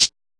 DIRTY SHAKR.wav